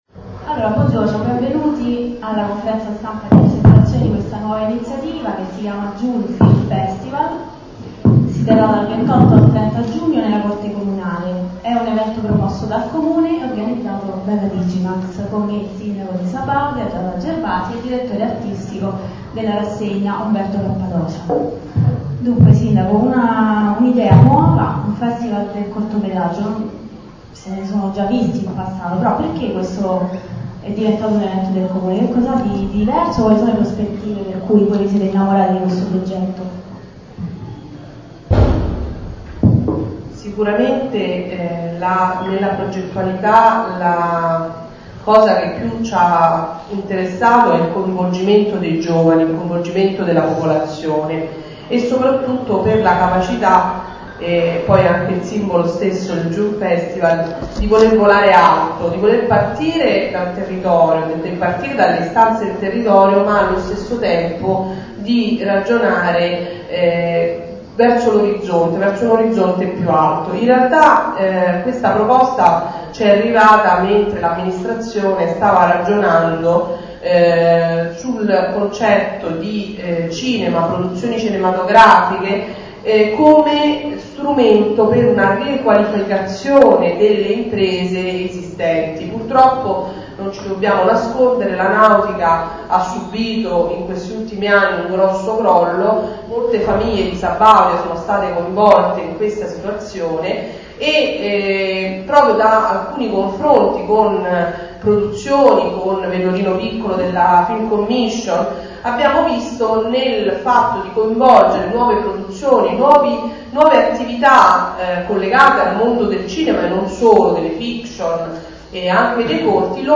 conferenza_junefestival.mp3